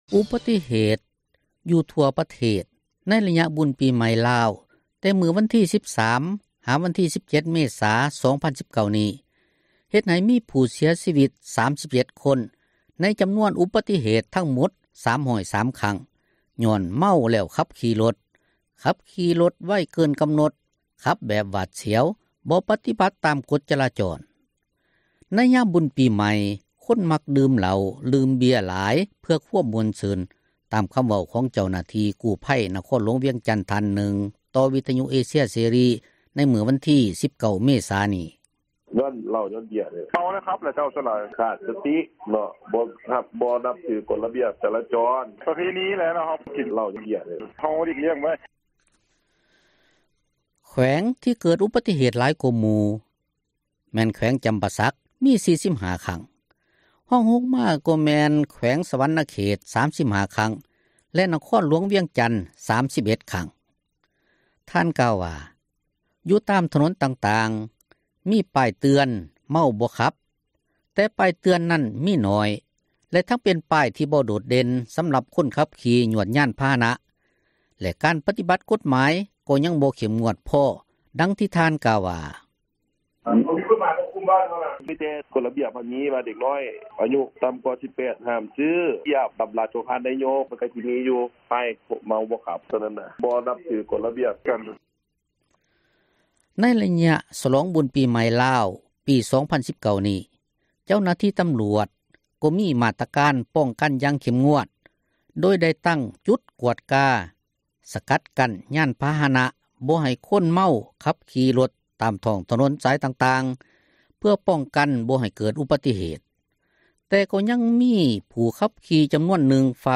ໃນຍາມບຸນປີໃໝ່ ຄົນມັກດື່ມເຫລົ້າ ດື່ມເບັຽຫຼາຍເພື່ອຄວາມມ່ວນຊື່ນ. ຕາມຄໍາເວົ້າຂອງເຈົ້າໜ້າທີ່ ກູ້ພັຍ ນະຄອນຫຼວງວຽງຈັນ ທ່ານນຶ່ງ ຕໍ່ວິທຍຸເອເຊັຍເສຣີ ໃນມື້ວັນທີ 19 ເມສາ ນີ້: